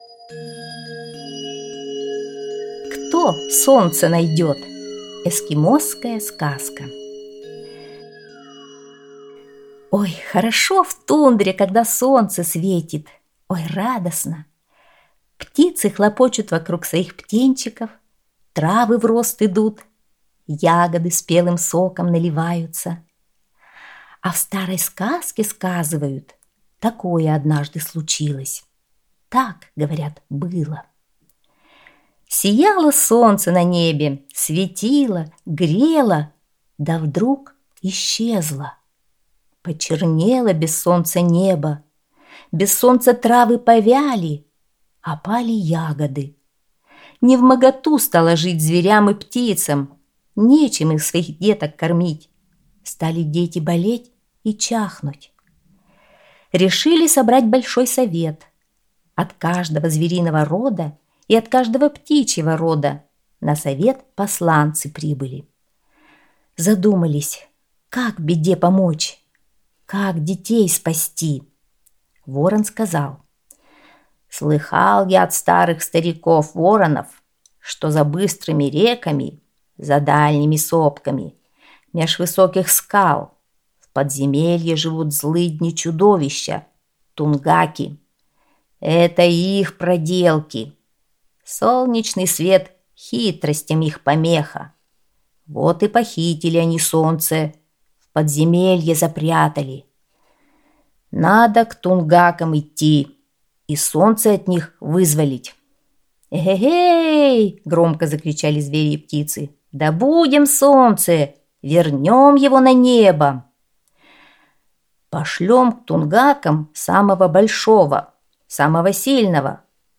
Эскимосская аудиосказка